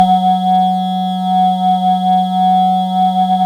SYN KLACK 02.wav